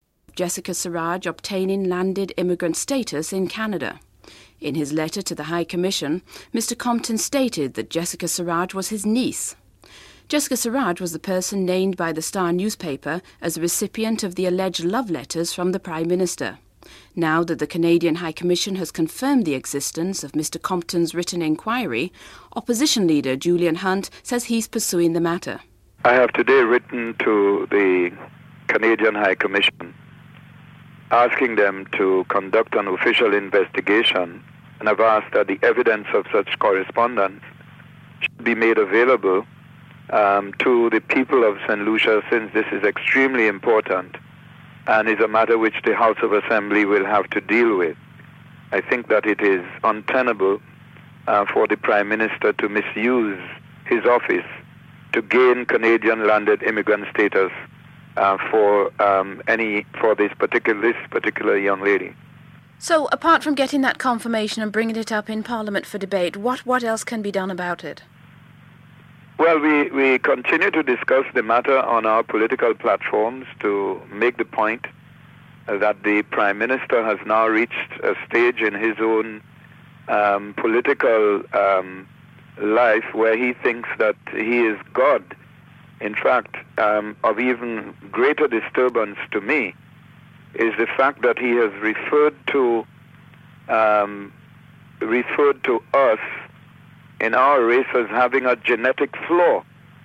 Report starts midway into first news item.